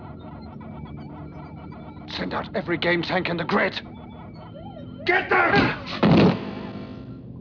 At 45:20 on the DVD, there is a Pac-Man and a string of his pac-dots on the right-hand side of the schematic in front of Sark! Even the sound effects in the background are from the Pac-Man video game!